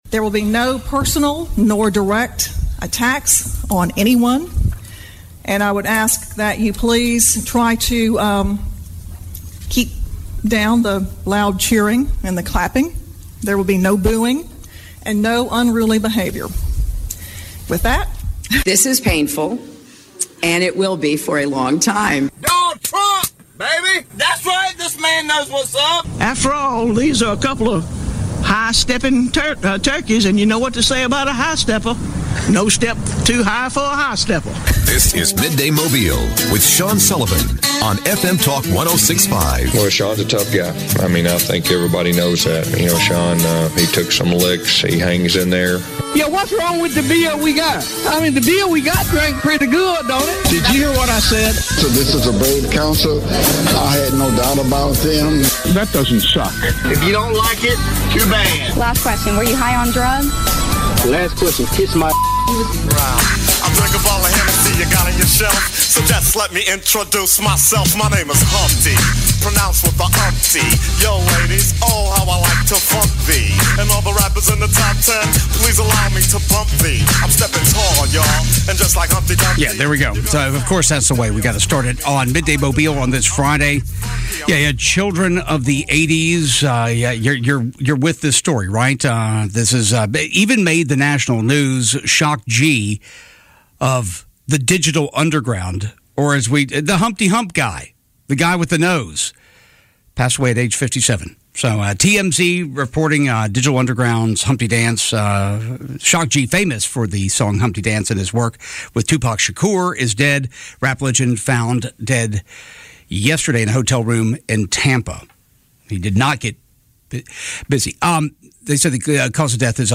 talks with State Rep. Chip Brown about what bills have passed, will pass, or won't pass as the Legislative Session comes to an end.